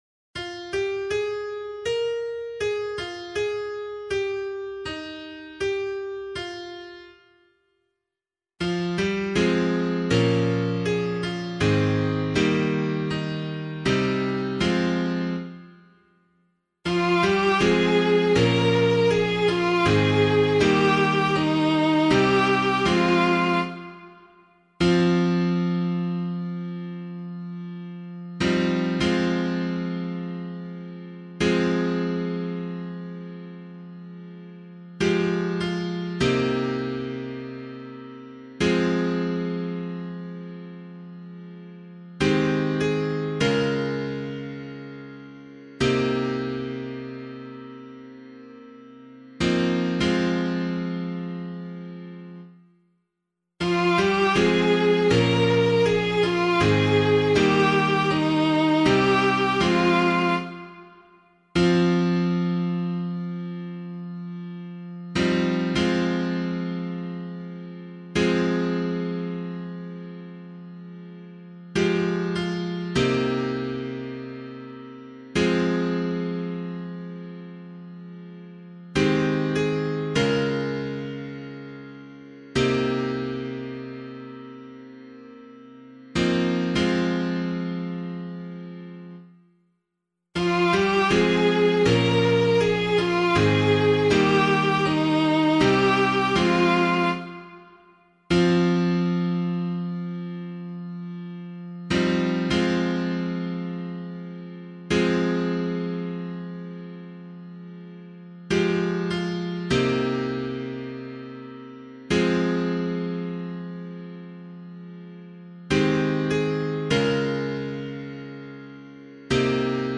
178 Joseph Psalm [APC - LiturgyShare + Meinrad 2] - piano.mp3